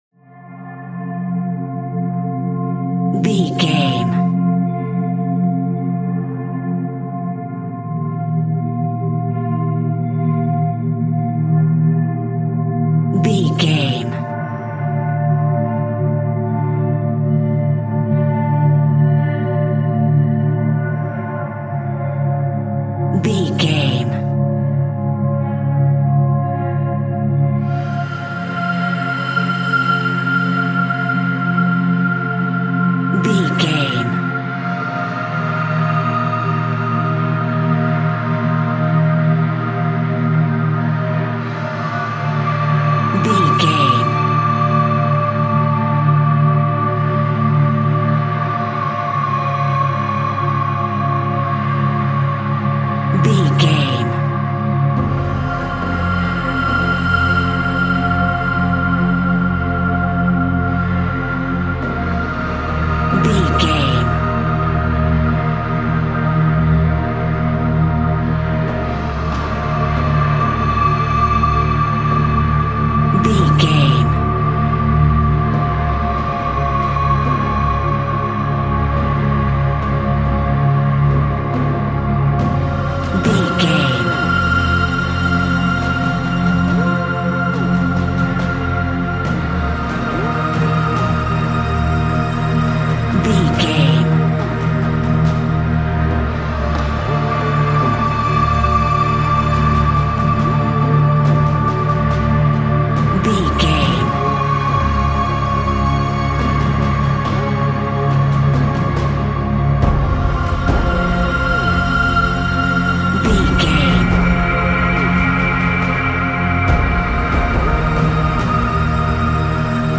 Ionian/Major
Slow
ambient
atmospheric
menacing
ominous
suspenseful